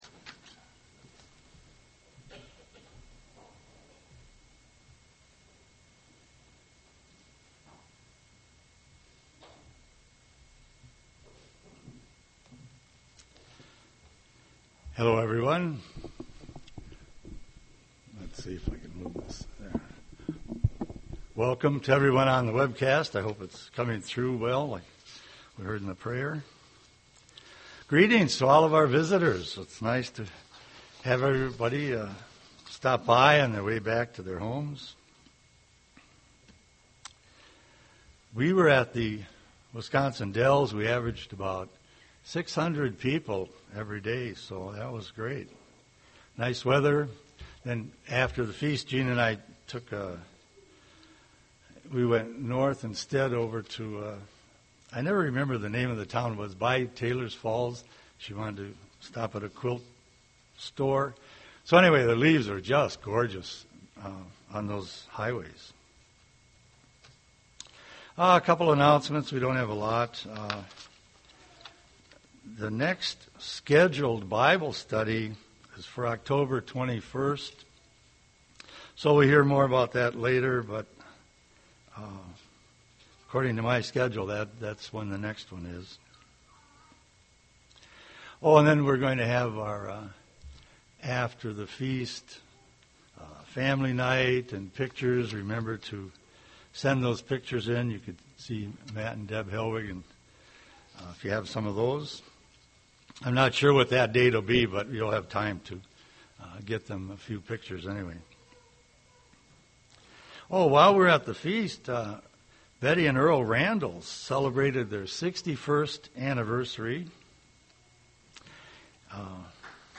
Given in Twin Cities, MN
UCG Sermon Kingdom of God righteousness focus Studying the bible?